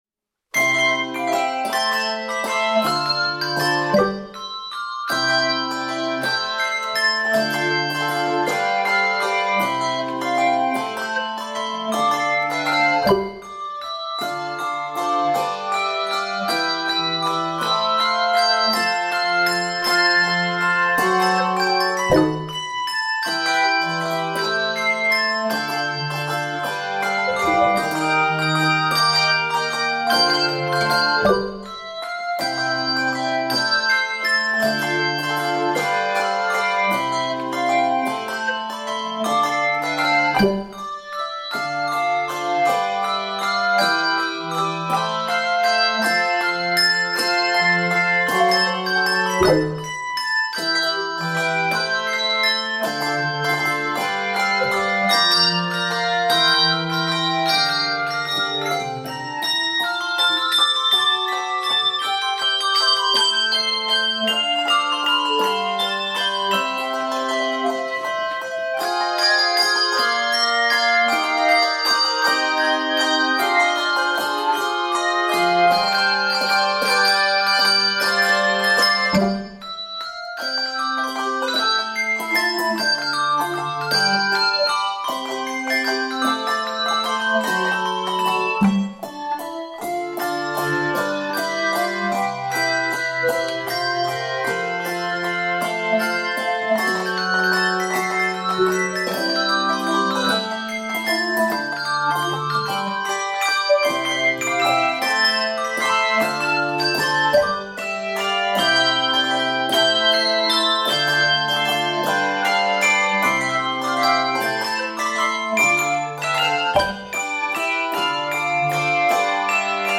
Key of C Major.